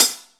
paiste hi hat8 close.wav